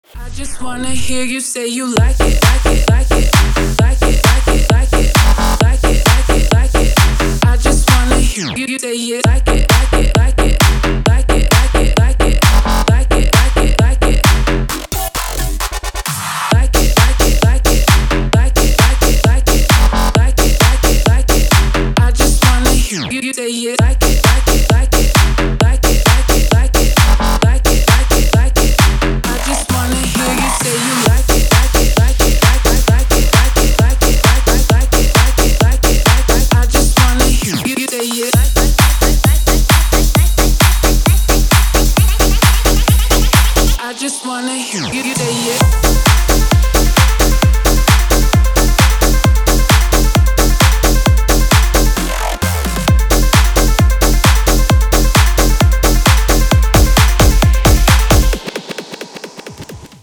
• Качество: 320, Stereo
женский вокал
Electronic
EDM
электронная музыка
мощные басы
Bass House
качающие
энергичные
electro house